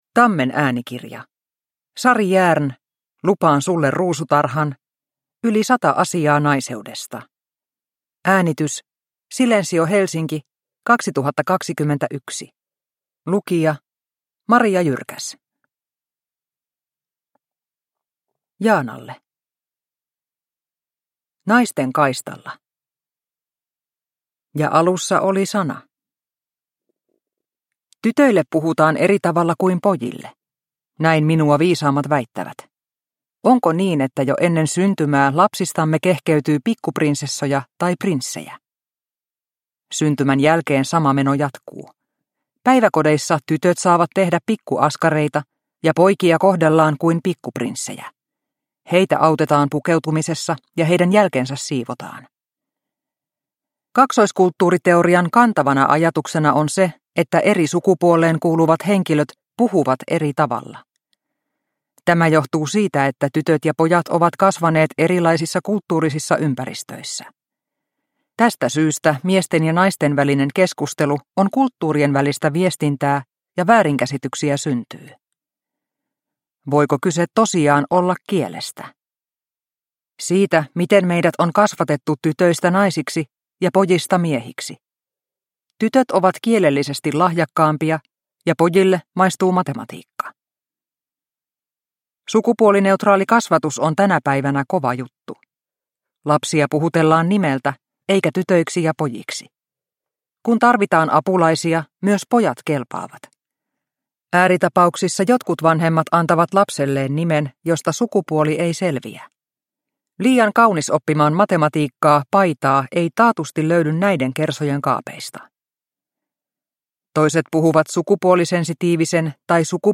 Lupaan sulle ruusutarhan – Ljudbok – Laddas ner